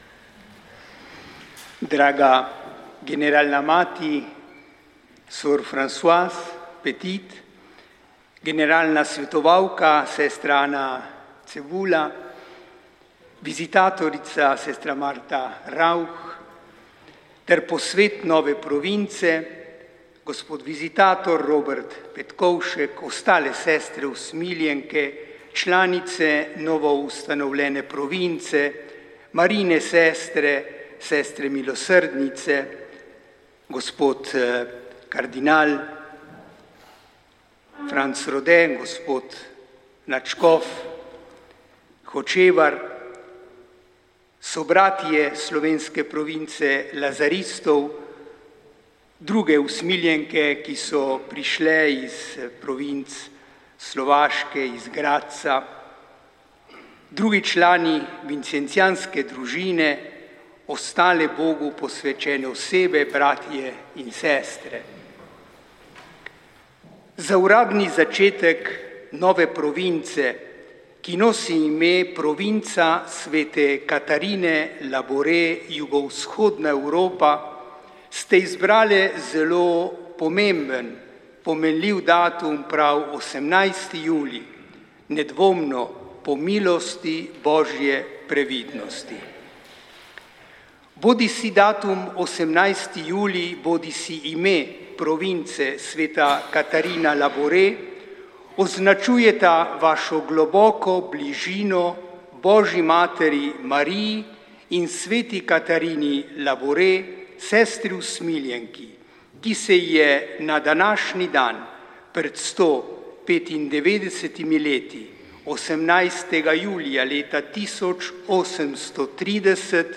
v studiu Radia Ognjišče